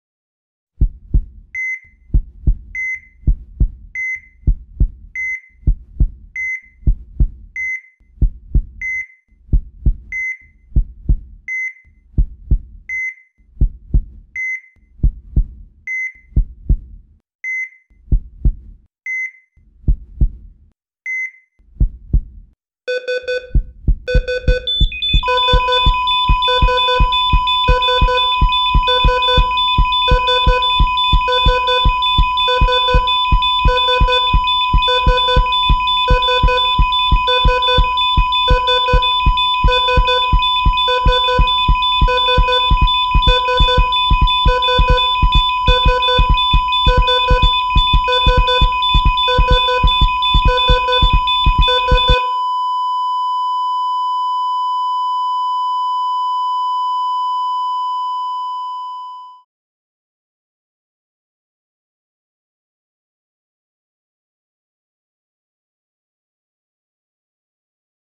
دانلود آهنگ قطع ضربان قلب 1 از افکت صوتی انسان و موجودات زنده
دانلود صدای قطع ضربان قلب 1 از ساعد نیوز با لینک مستقیم و کیفیت بالا
جلوه های صوتی